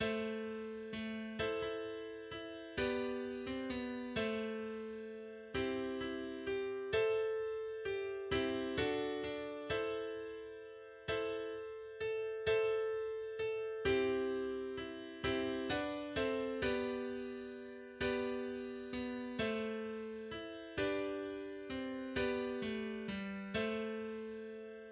Text & Melodie Volkslied
\version "2.12.3" \language "deutsch" \header { tagline = "" } \layout { indent = #0 } akkorde = \chordmode { \germanChords \set chordChanges = ##t a1*3/4:m a:m g a:m c a:m c4 d2 a1*3/4:m a:m a:m c c4 h4:m a4:m g1*3/4 g a:m g g a:m } global = { \autoBeamOff \tempo 4 = 130 \time 3/4 \key c \major } melodie = \relative c' { \global a2 a4 e'8 e4. e4 h4. c8 h4 a2. r4 e' g a2 g4 e4 fis d e2.( e4) r a a2 a4 g2 e4 e4 d c h2.( h2) h4 a2 e'4 d2 c4 h4 a g a2.